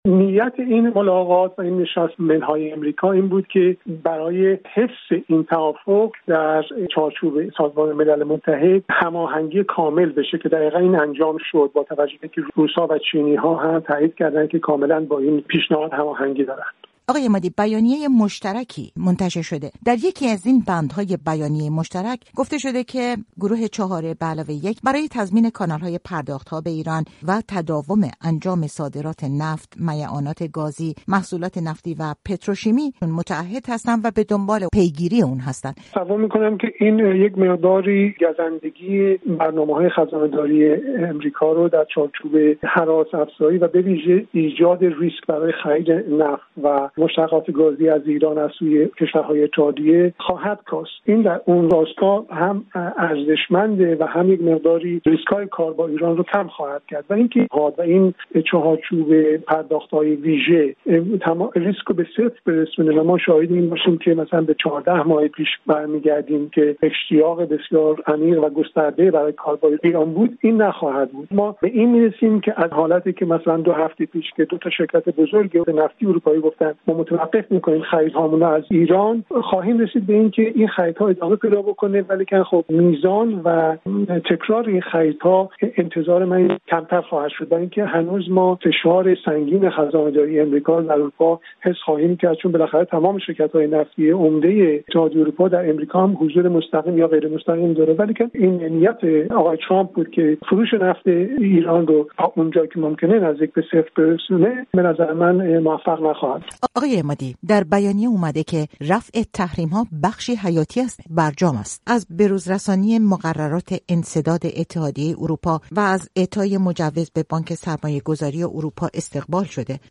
تصمیمات اتحادیه اروپا درباره برجام در گفت‌وگو